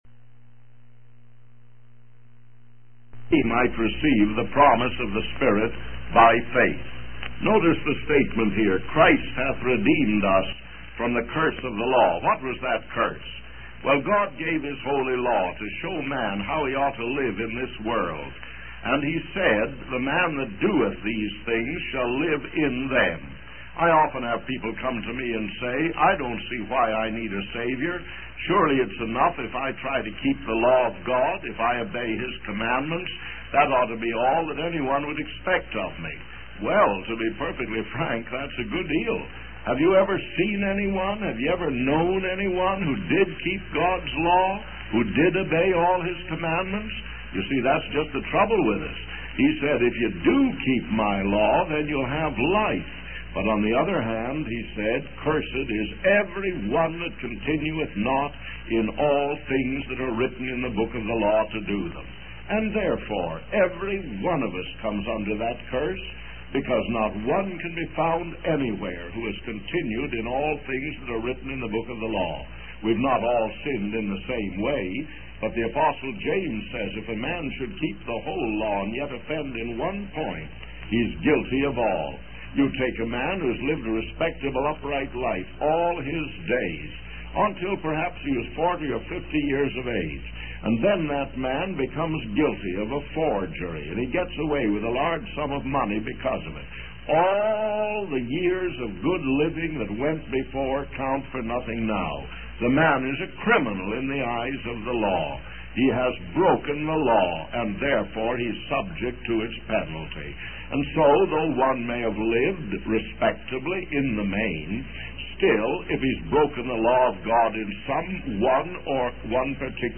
In this sermon, the preacher emphasizes the importance of receiving Jesus into one's heart and establishing a blessed and holy fellowship with Him.